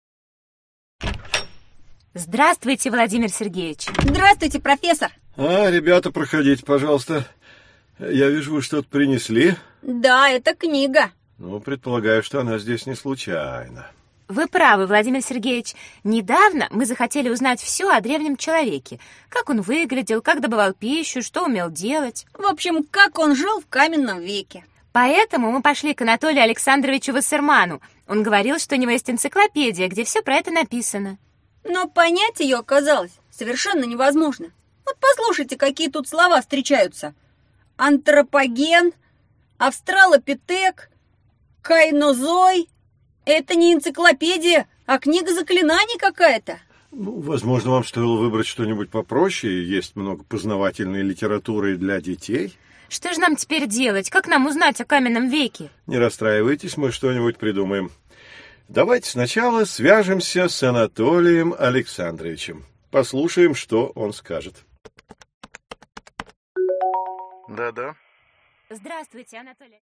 Аудиокнига История Земли: Каменный век | Библиотека аудиокниг